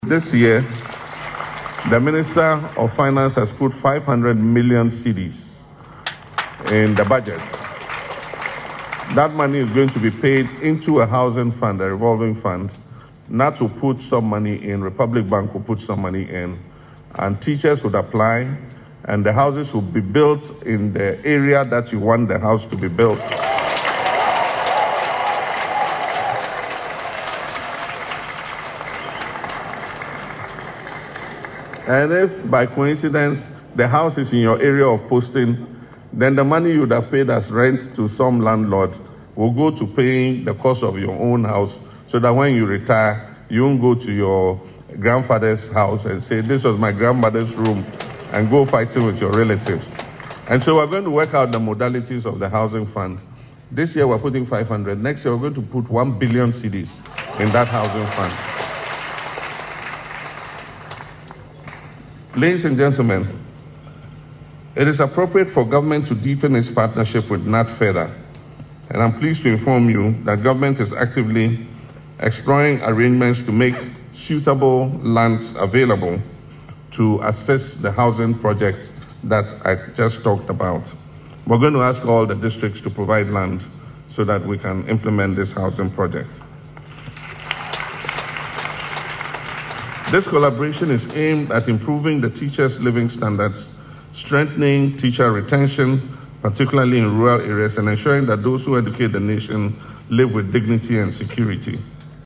President Mahama made this commitment while addressing the Ghana National Association of Teachers (GNAT) 7th Quadrennial (54th) National Delegates Conference at the University of Professional Studies, Accra, on Monday, January 5, 2026.